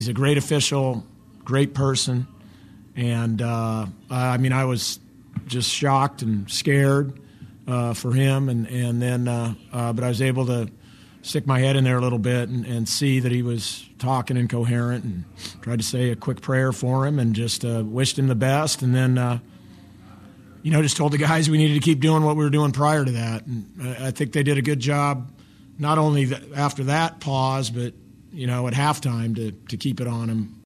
“I was able to stick my head in there a little bit and see that he was talking and coherent and tried to say a quick prayer for him and just wished him the best,” Gonzaga coach Mark Few said after the game.